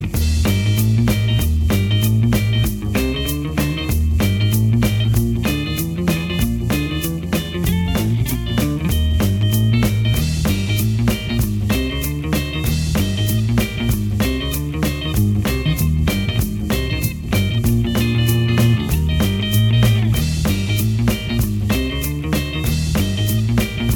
Minus Slide Guitar And Solo Pop (1960s) 1:55 Buy £1.50